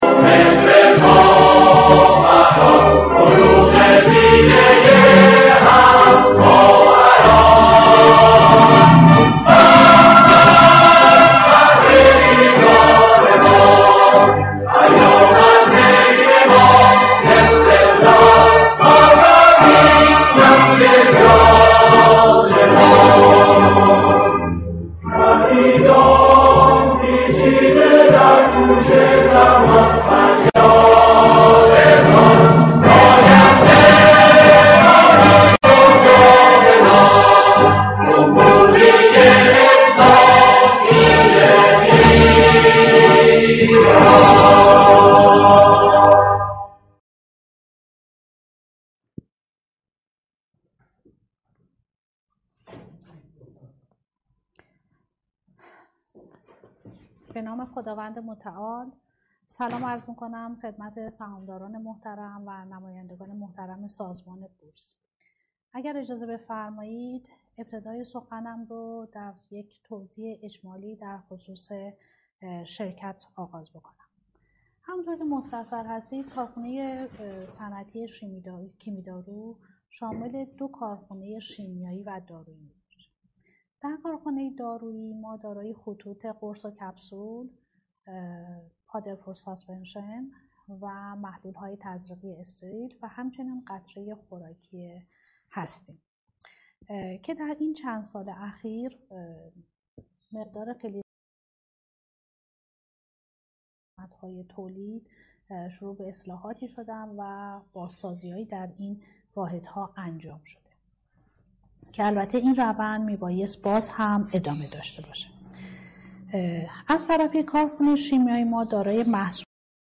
کنفرانس آنلاین پرسش و پاسخ سهامداران و مدیران شرکت صنعتی کیمیدارو